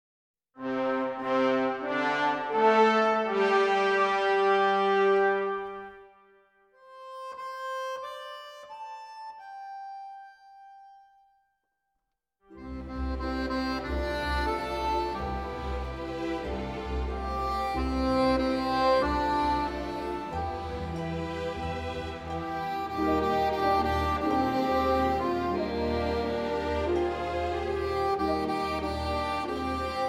Accordion soloist